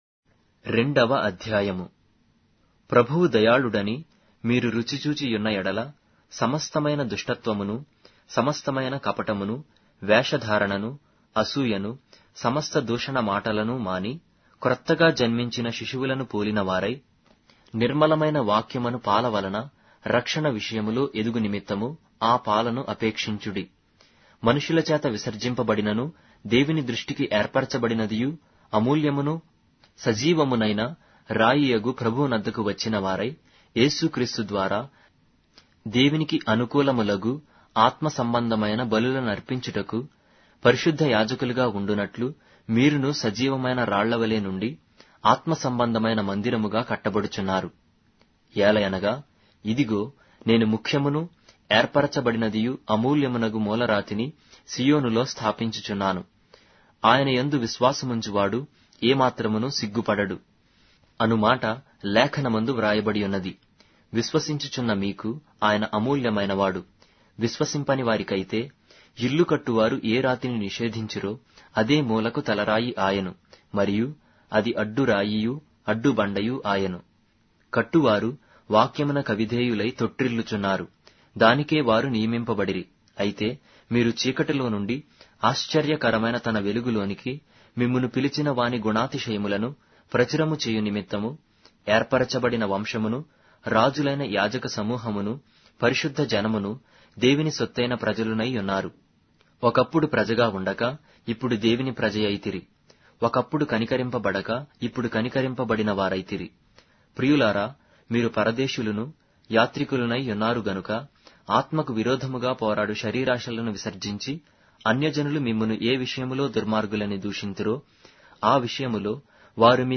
Telugu Audio Bible - 1-Peter 2 in Net bible version